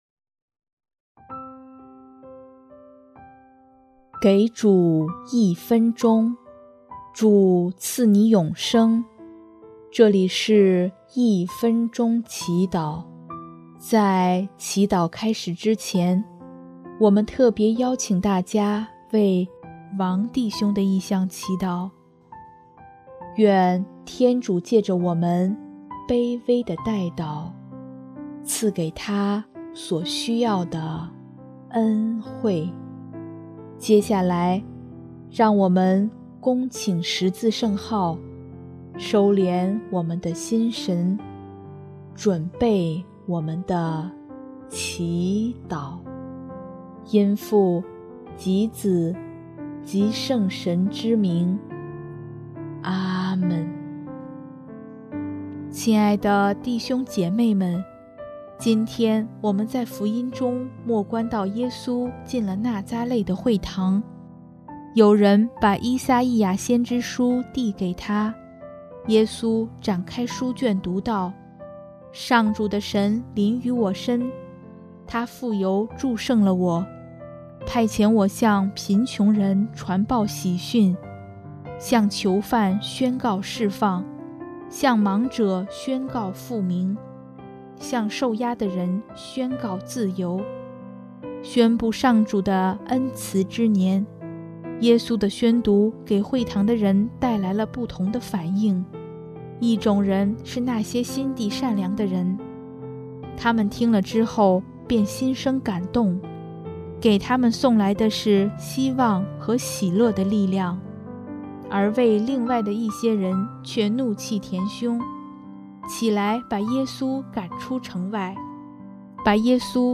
音乐：主日赞歌《你的君王已来到》